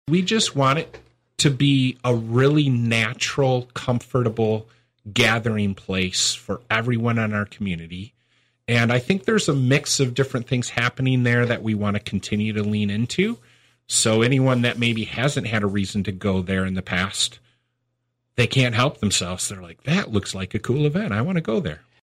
One is an update on the Heeringa Holland Civic Center Place, 12 months after the governing panel approved a five-year Facility Management Agreement with Sports Facilities Management to operate the facility. City Manager Keith Van Beek talked about what the vision for the Civic Center is.